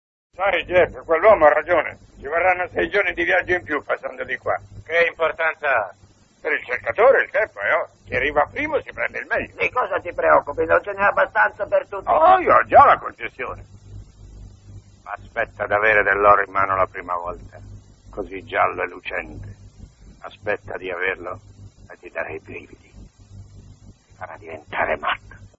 voce di Olinto Cristina nel film "Terra lontana", in cui doppia Jay C. Flippen.